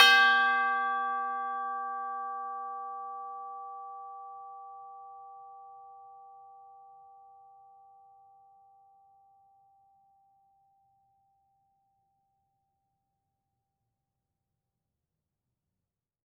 Percussion
TB_hit_G4_v4_rr1.wav